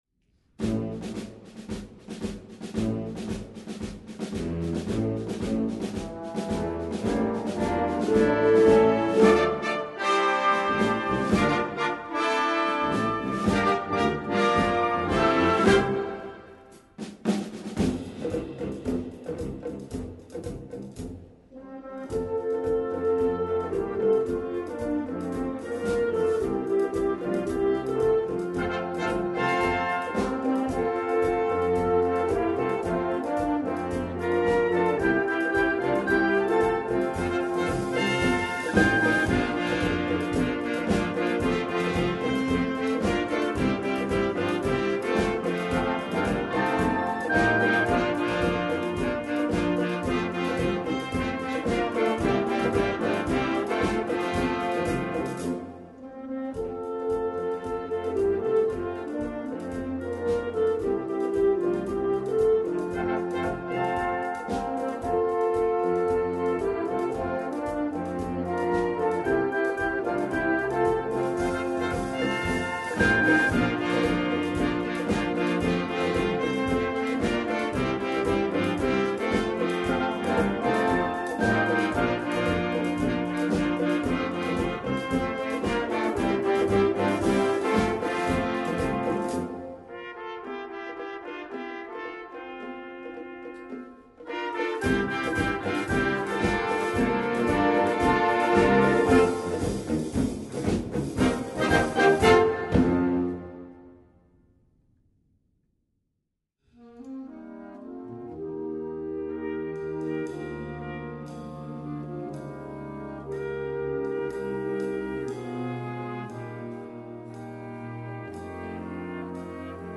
Gattung: Gospel Suite
Besetzung: Blasorchester
Three Movements